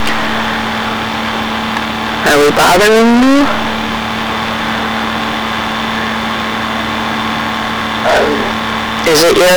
These recordings were taken using a General Electric Microcassette Recorder, Model #3-5326A using a Radio Shack MC-60 Microcassette at normal speed.
"Are we bothering you?" - If you listen carefully, it sounds like they're saying "I hate it."